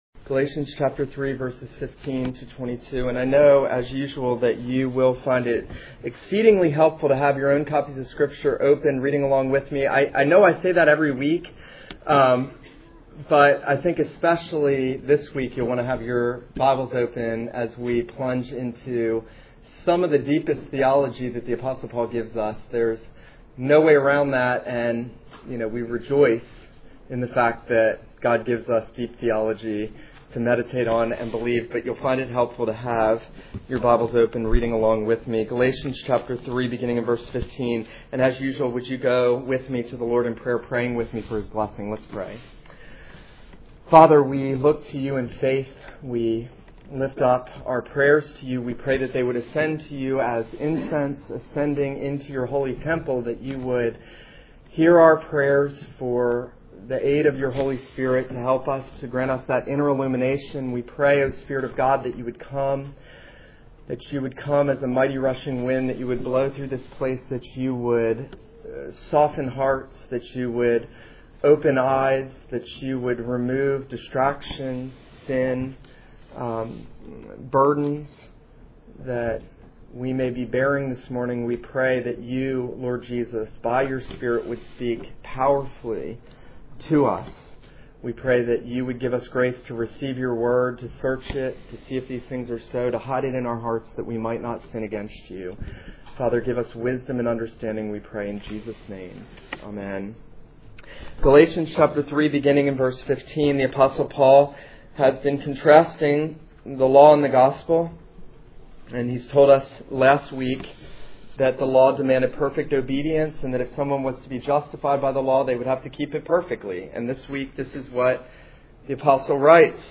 This is a sermon on Galatians 3:15-22.